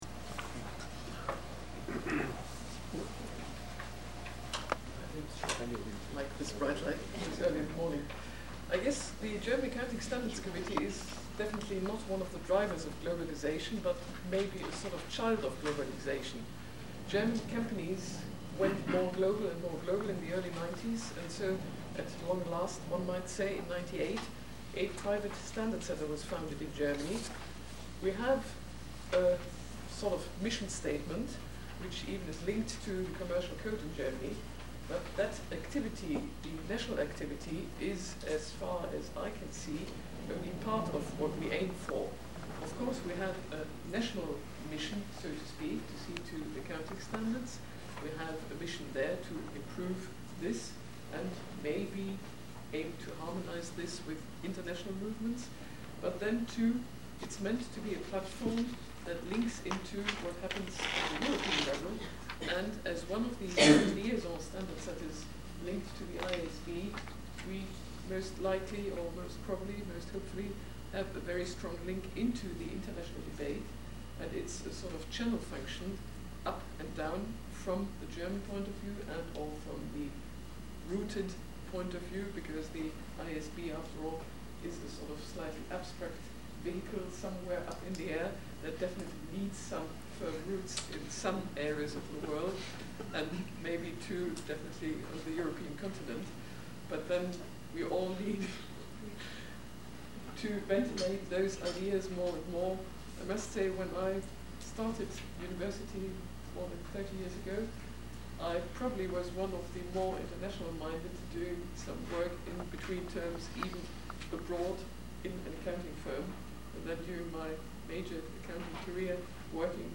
The GSAR speakers in Berlin were not wearing microphones, so the only audio captured was through the microphone built into my old Sony camcorder.